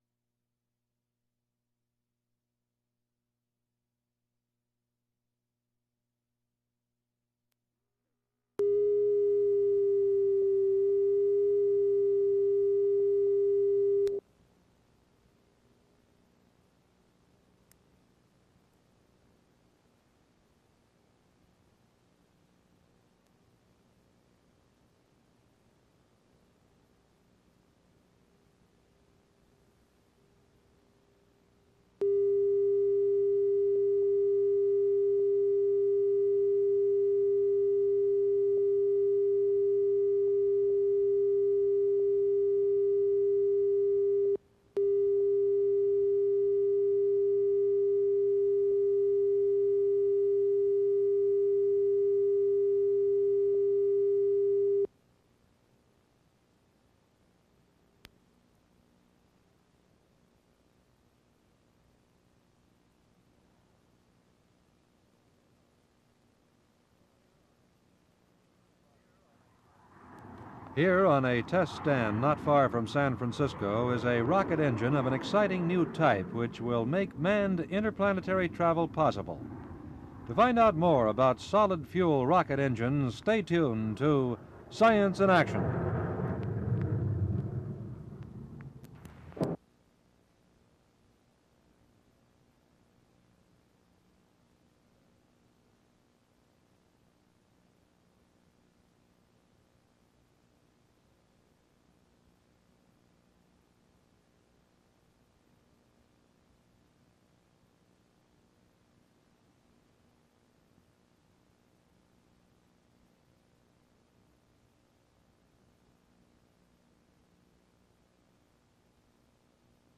Science in Action - Solid Fuel Engines (1961) ( first 2 mins - a bit glitchy )